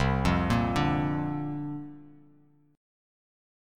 Listen to C9sus4 strummed